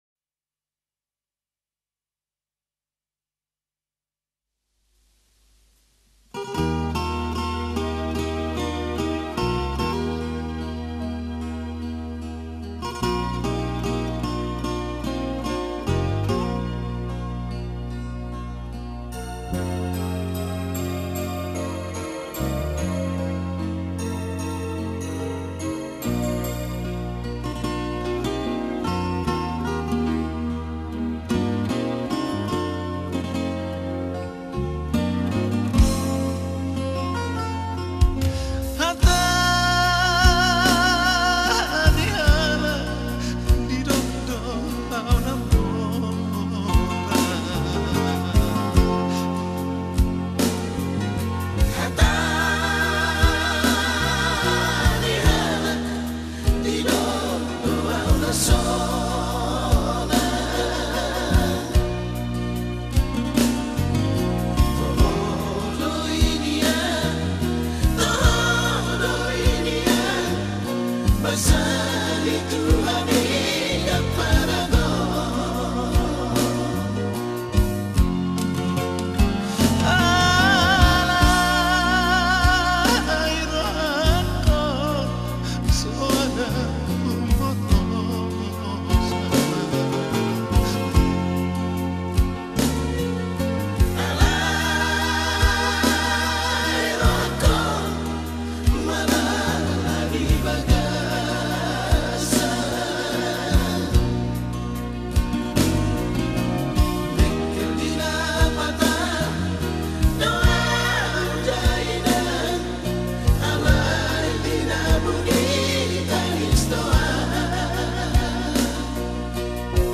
penyanyi lagu batak legendaris